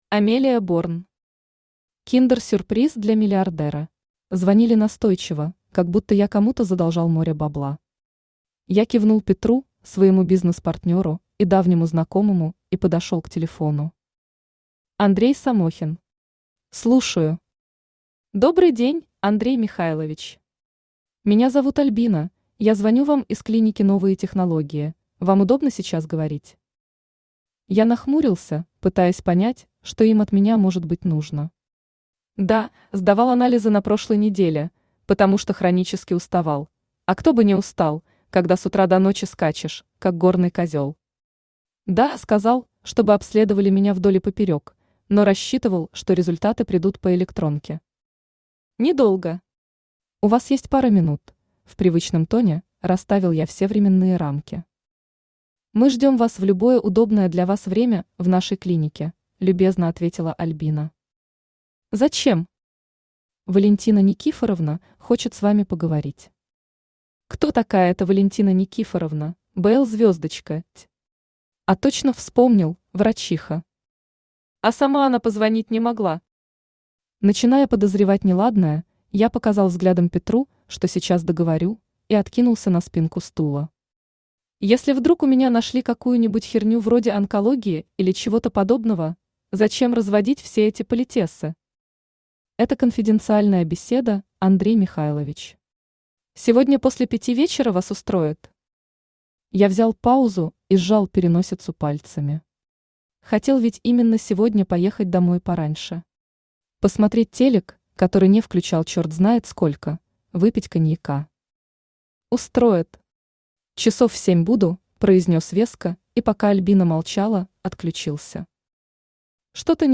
Аудиокнига Киндер-сюрприз для миллиардера | Библиотека аудиокниг
Aудиокнига Киндер-сюрприз для миллиардера Автор Амелия Борн Читает аудиокнигу Авточтец ЛитРес.